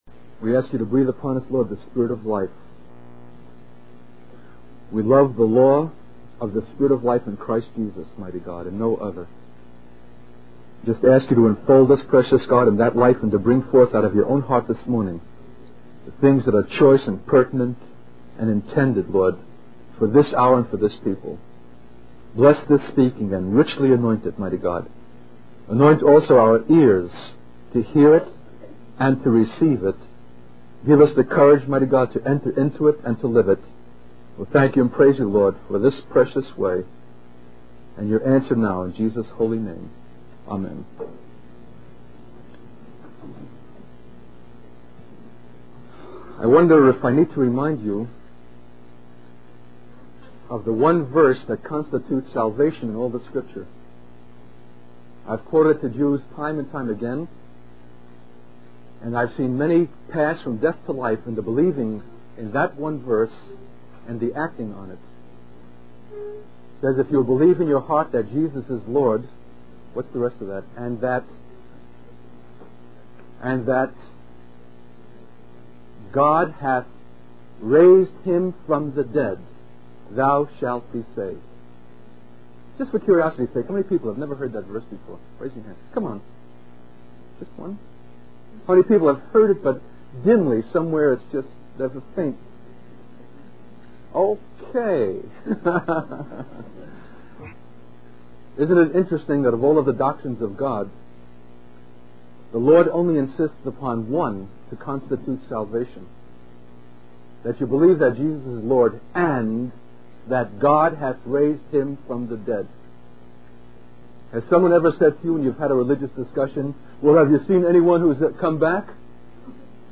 In this sermon, the speaker emphasizes the importance of not engaging in futile arguments with those who oppose the faith. He encourages believers to rely on the life of Christ, His wisdom, and power to navigate the complexities of the last days.